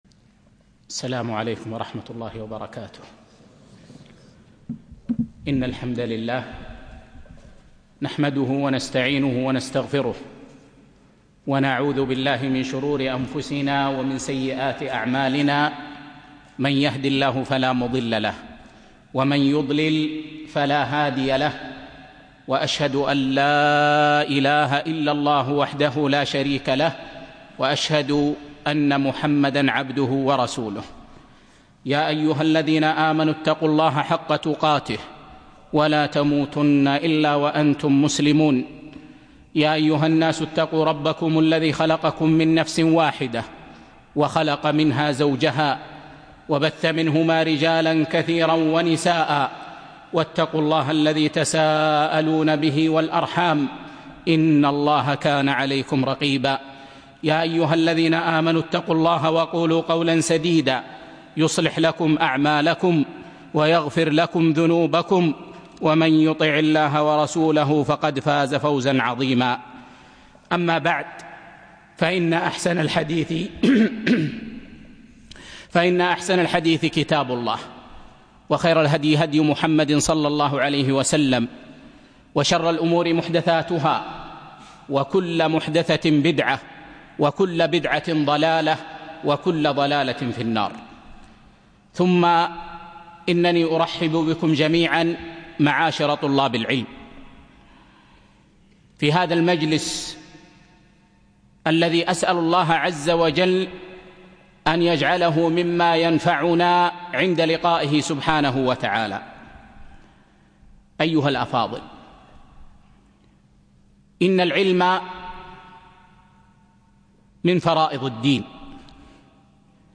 1- قواعد في المعاملات المالية (1) - الدرس الاول